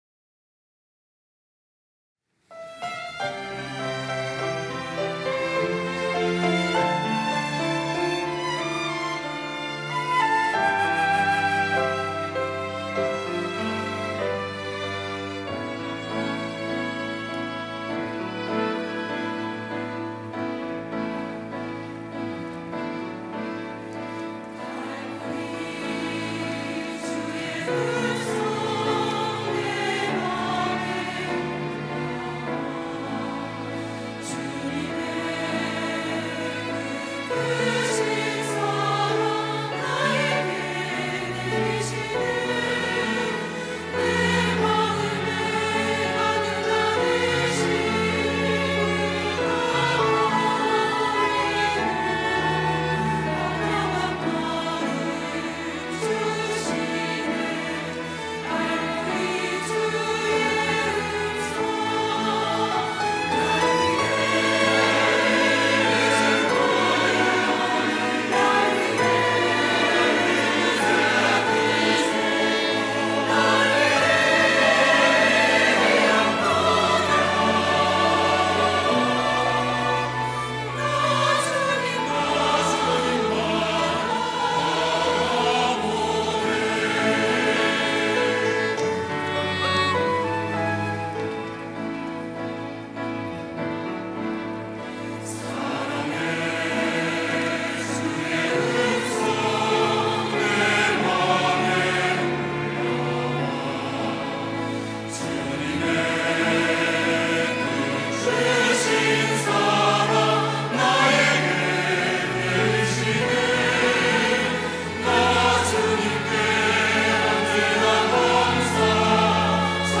호산나(주일3부) - 보혈의 은혜
찬양대